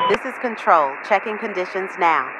Radio-atcWeatherUpdate3.ogg